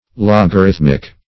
Logarithmic \Log`a*rith"mic\, Logarithmical \Log`a*rith"mic*al\,